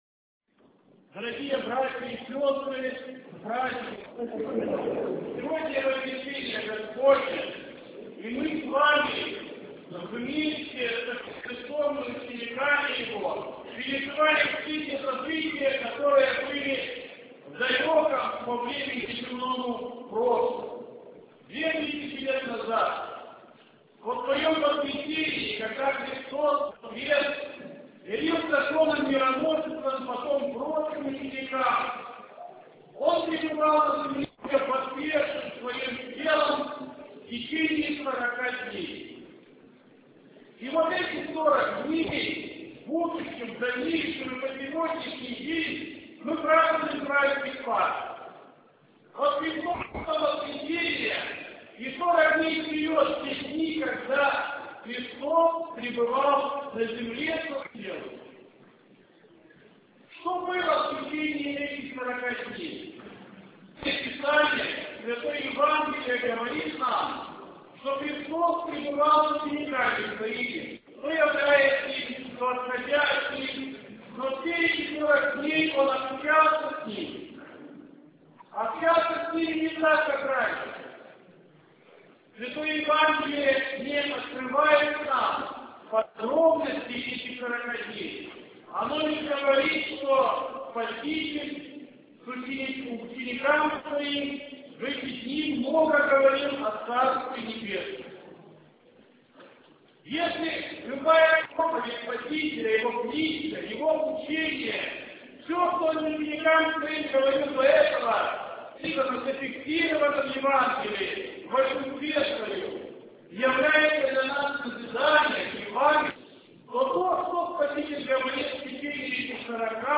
21 мая, в день праздника Вознесения Господа и Бога и Спаса нашего Иисуса Христа, викарий Киевской Митрополии епископ Боярский Феодосий совершил Божественную Литургию в Крестовоздвиженском храме г.Киева. Его Преосвященству сослужило духовенство храма.
В завершение богослужения был совершен чин Славления, после которого епископ Феодосий произнес проповедь.